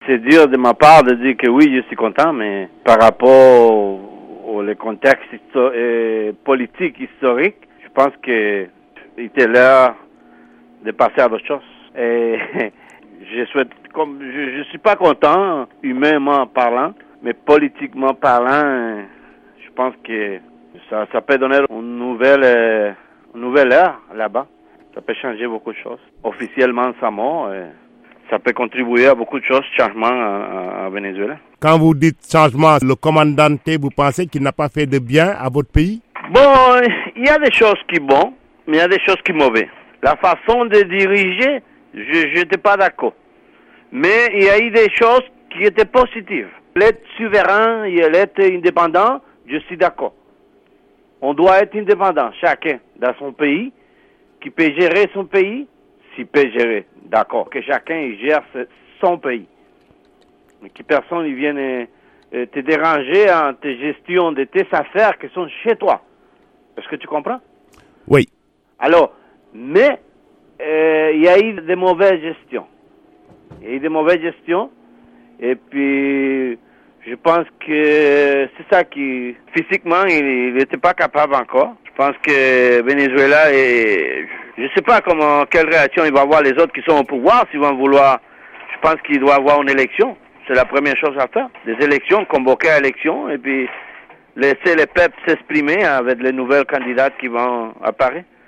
un Vénézuélien en exil à Québec au Canada